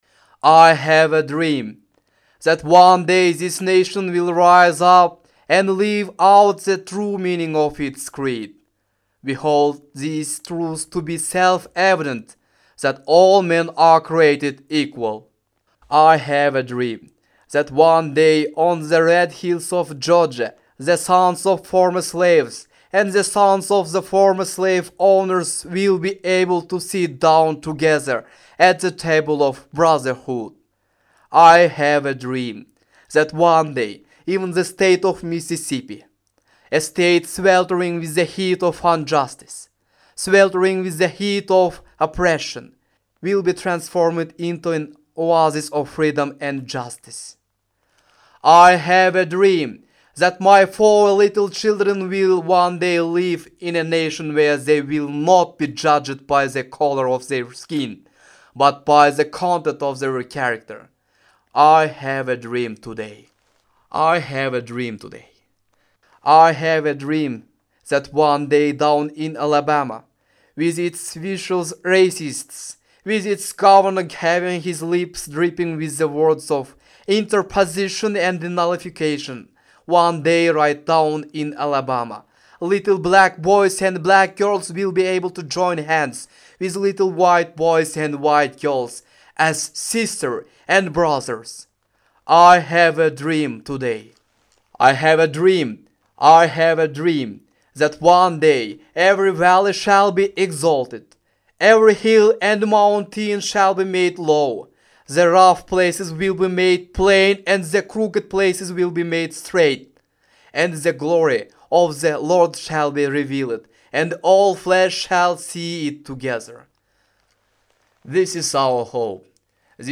Аудиофайл представлен на конкурс "iDream" в честь 50-летия речи Мартина Лютера Кинга "У меня есть мечта". Для участия в конкурсе необходимо было записать отрывок из речи Мартина Лютера Кинга «У меня есть мечта» на кыргызском, русском или английском языке.